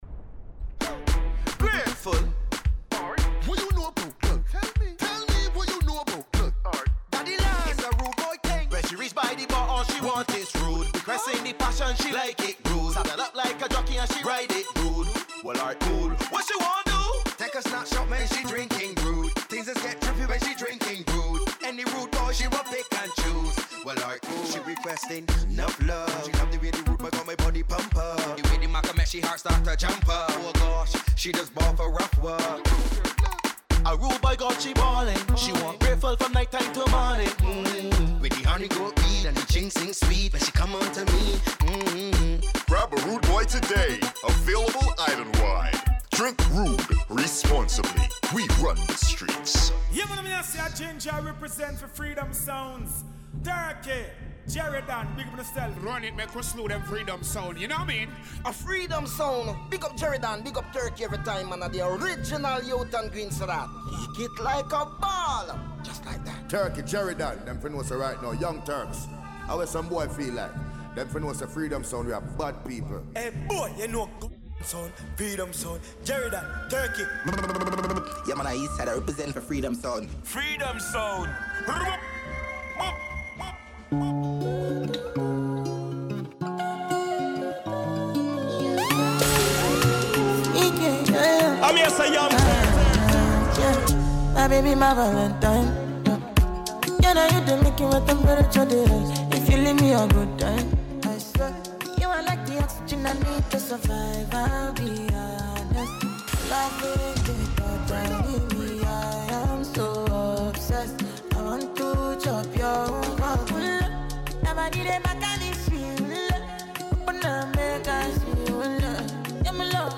pure vibes, real energy, and non-stop party mode